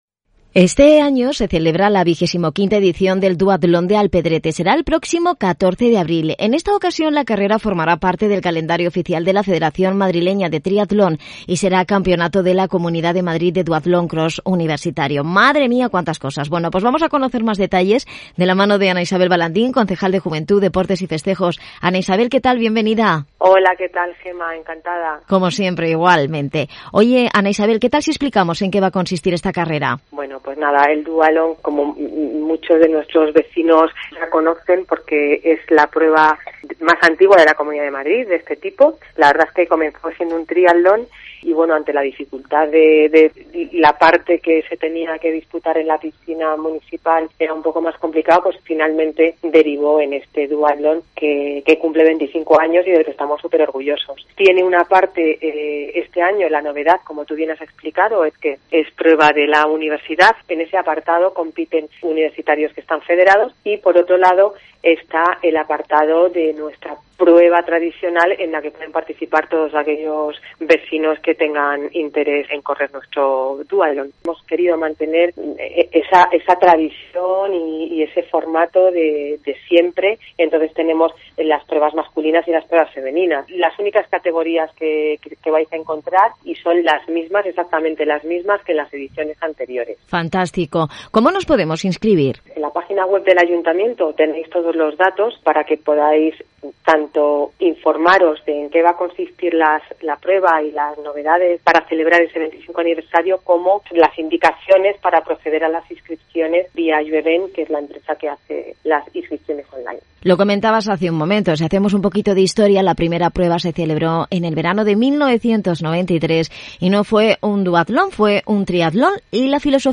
25-aniversario-del-duatlon-cross-alpedrete-entrevista-ana-isabel-balandin-concejala-deportes-cope-la-sierra